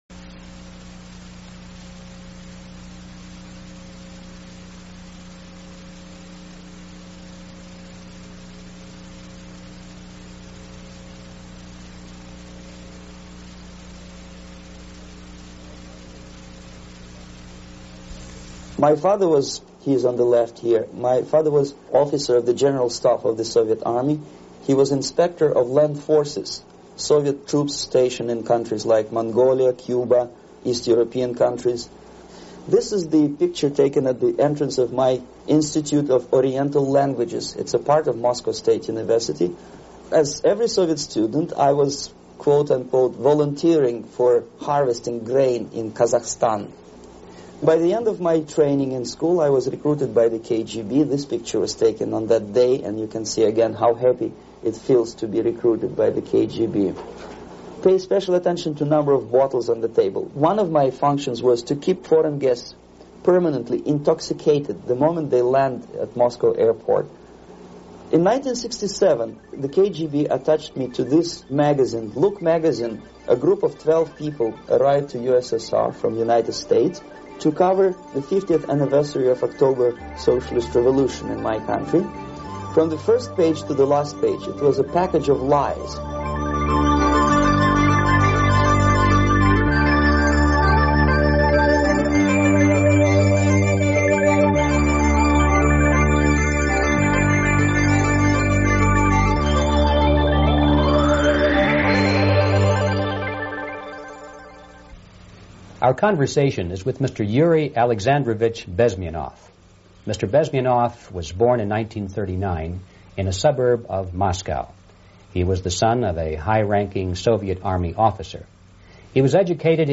FULL INTERVIEW with Yuri Bezmenov: The Four Stages of Ideological Subversion (1984)